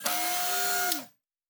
pgs/Assets/Audio/Sci-Fi Sounds/Mechanical/Servo Small 4_1.wav at master
Servo Small 4_1.wav